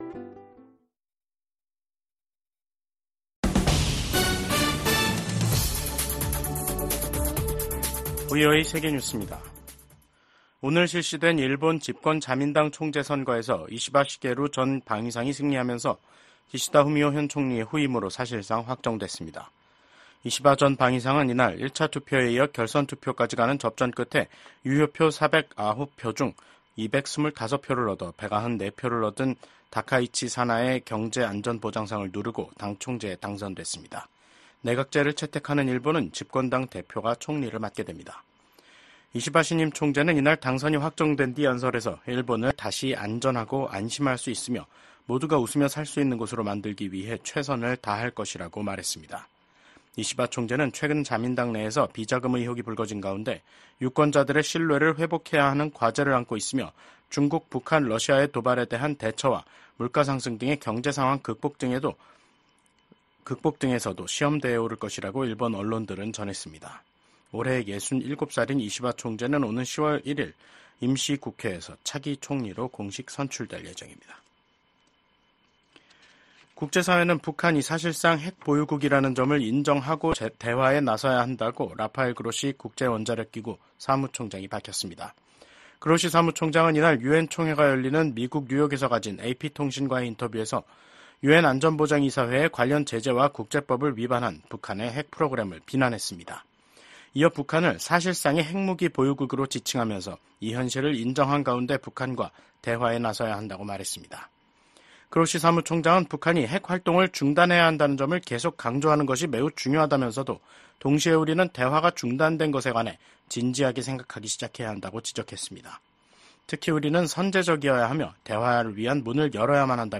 VOA 한국어 간판 뉴스 프로그램 '뉴스 투데이', 2024년 9월 27일 2부 방송입니다. 미국 대북 정책의 주요 요소는 종교와 신앙의 자유에 대한 정보를 포함한 북한 내 정보 접근을 확대하는 것이라고 미국 북한인권특사가 밝혔습니다. 미국과 영국, 호주의 안보협의체인 오커스가 첨단 군사기술 개발 협력 분야에서 한국 등의 참여 가능성을 논의 중이라고 확인했습니다.